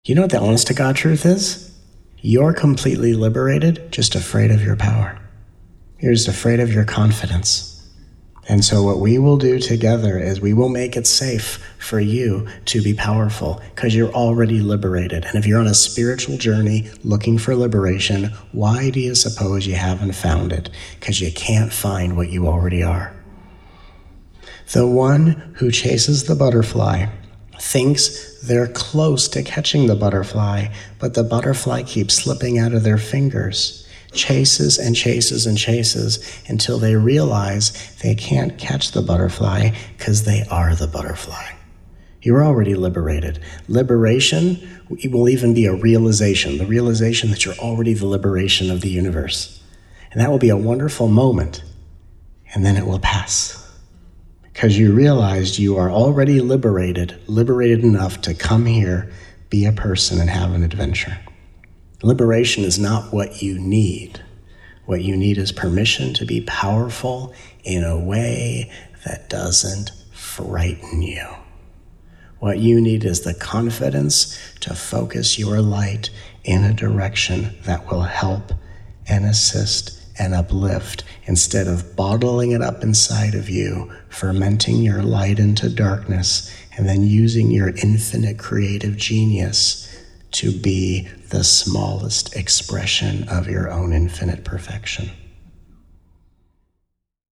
Please join us for these 17+ hours of nourishing, uplifting, often hilarious, peaceful, and powerful teachings from this miraculous 5-day Soul Convergence.